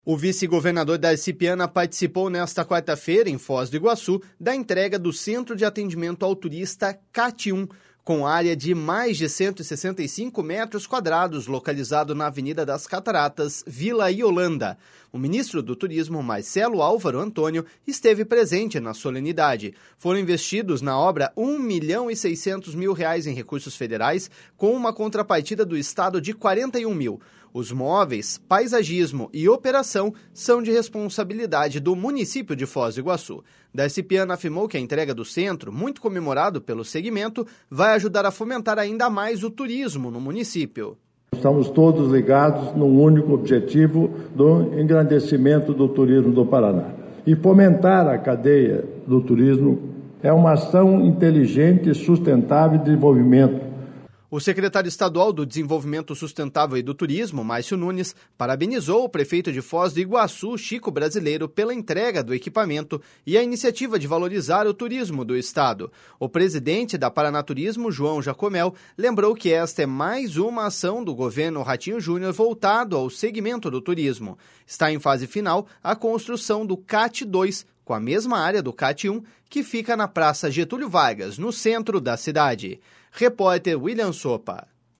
Darci Piana afirmou que a entrega do Centro, muito comemorado pelo segmento, vai ajudar a fomentar ainda mais o turismo no município.// SONORA DARCI PIANA.//